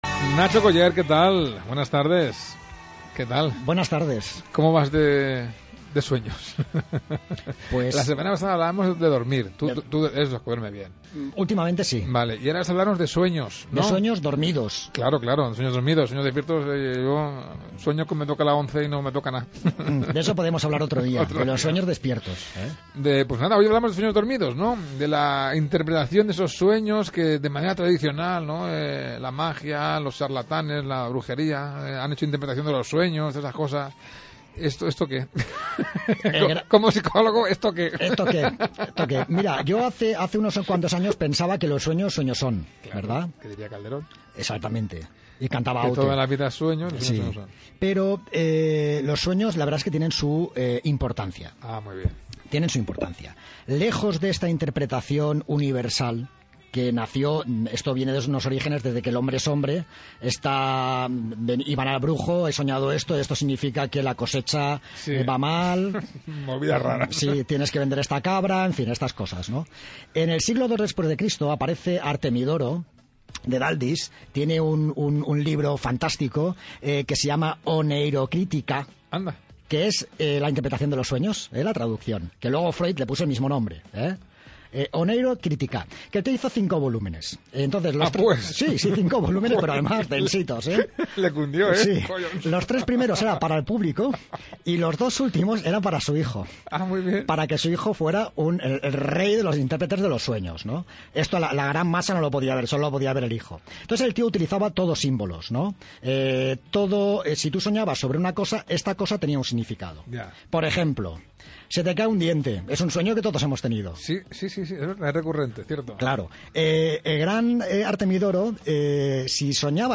Te dejo en este post el programa de radio que dura aproximadamente 15 minutos y del que seguro te llevas alguna cosa interesante, así como unos enlaces de diferentes artículos que he escrito o he colaborado sobre el tema.